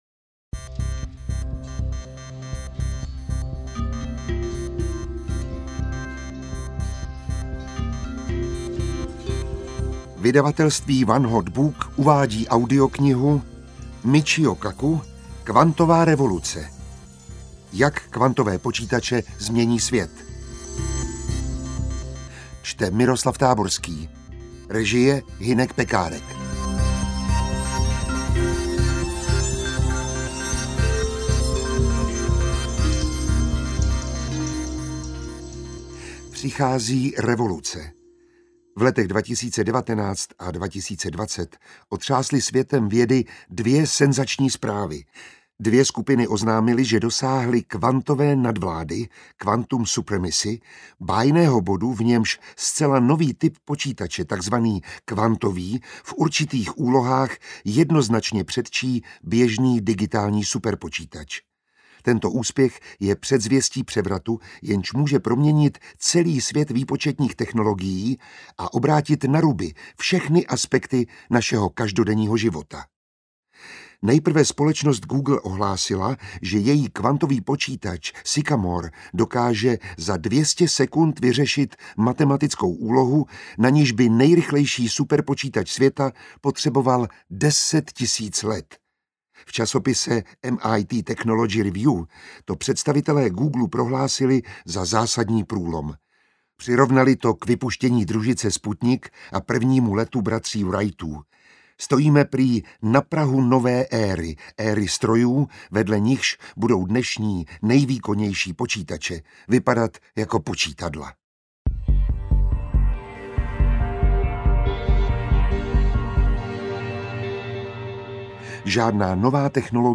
Ukázka z knihy
• InterpretMiroslav Táborský